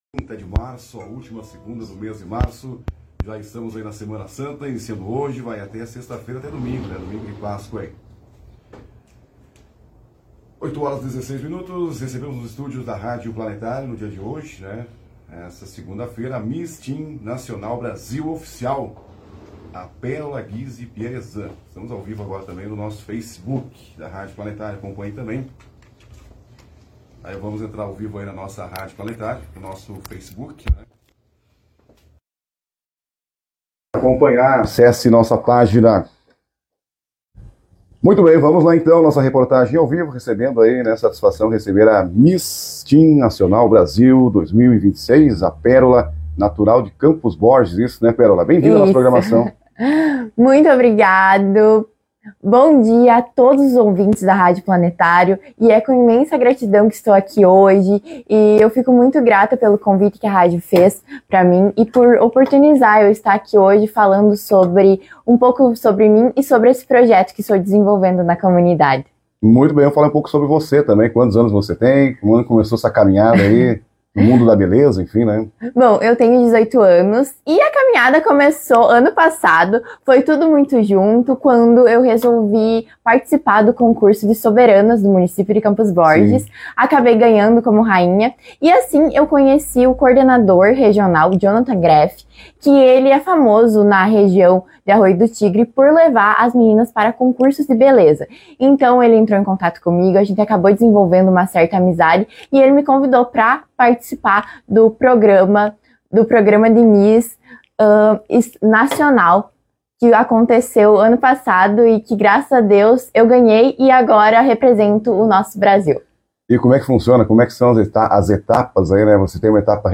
A Rádio Planetário recebeu nos estúdios